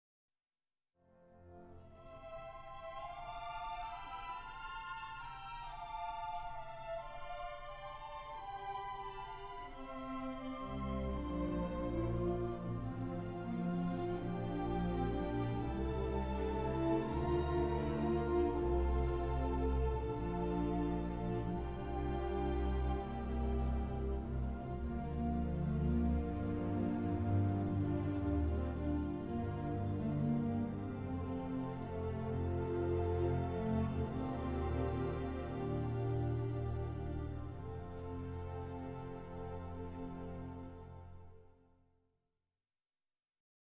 iii. Lullaby is a pastorale with muted strings.